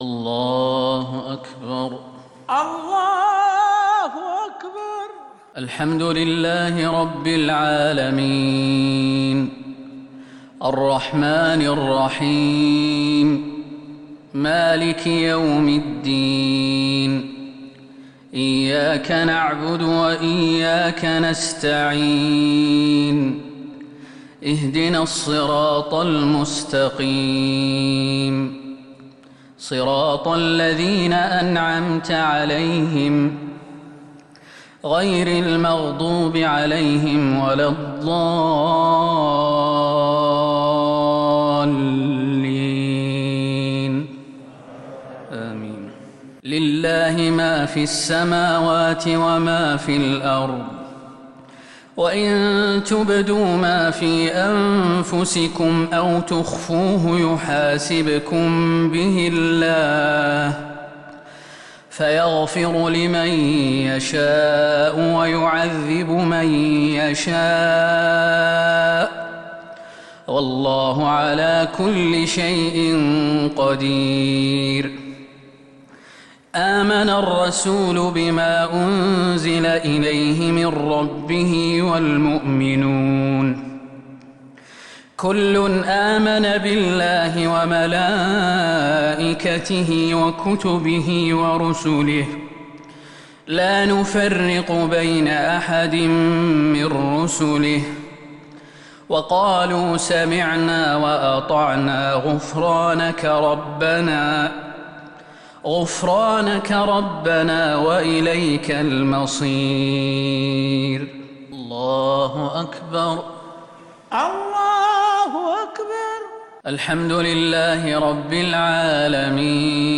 صلاة المغرب